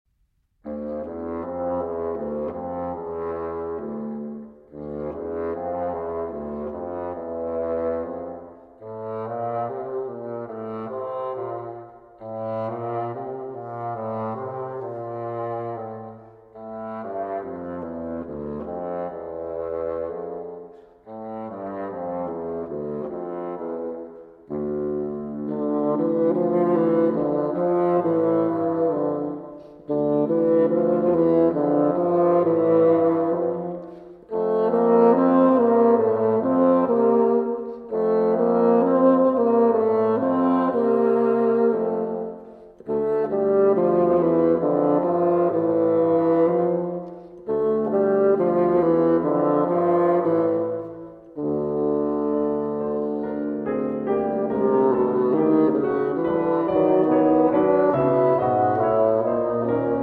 Suite for Two Bassoons and Piano